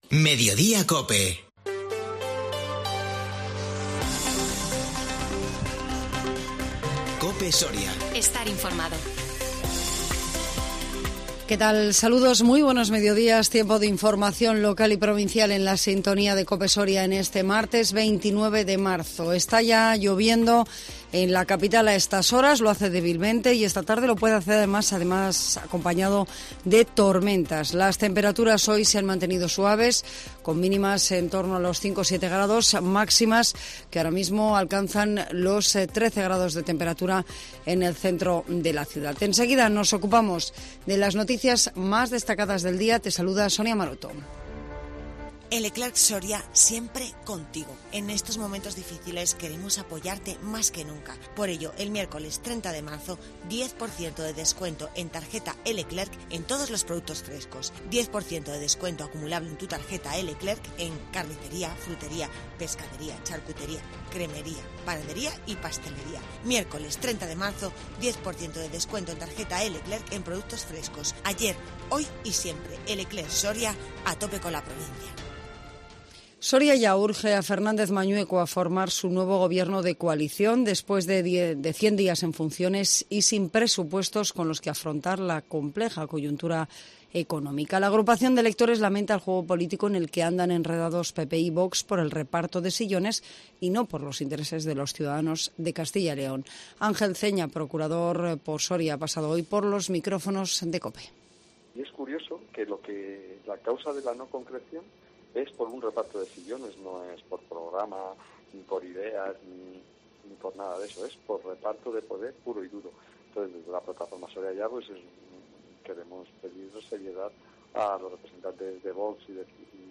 INFORMATIVO MEDIODÍA COPE SORIA 29 MARZO 2022